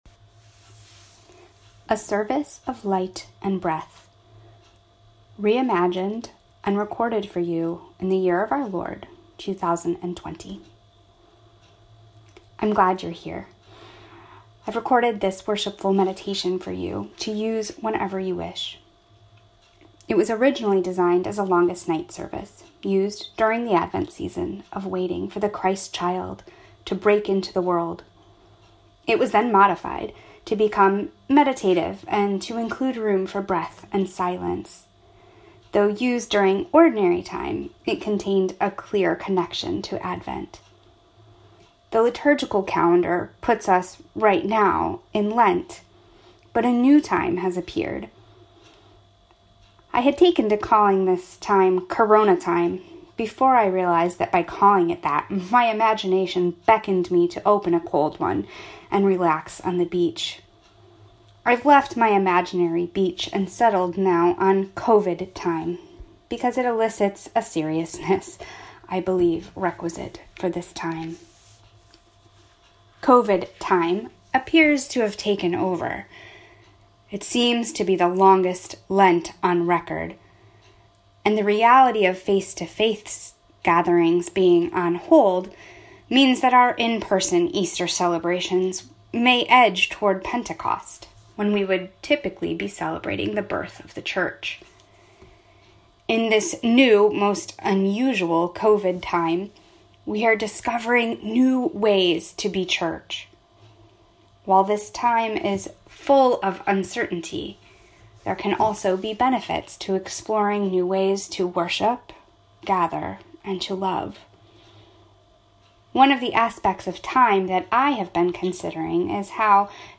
I have recorded this worshipful meditation for you to use whenever you wish.
It was later modified to become more meditative and include room for breath and silence.